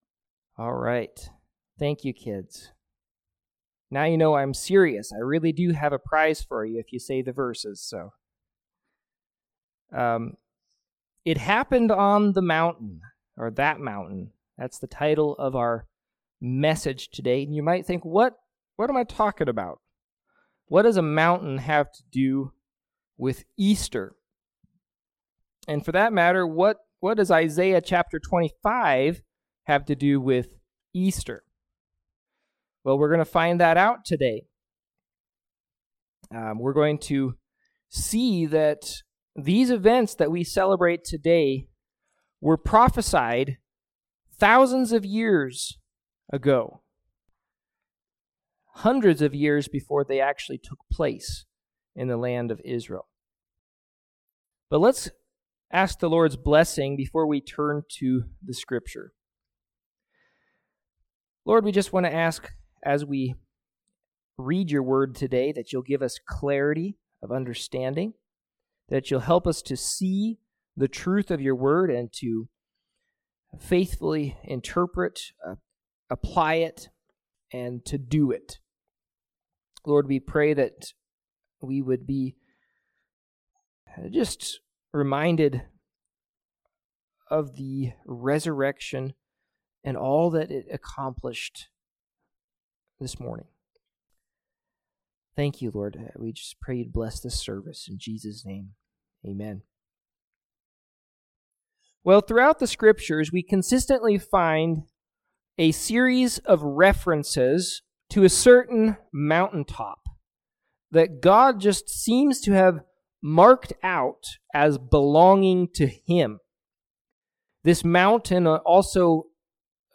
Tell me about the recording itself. Service Type: Morning Sevice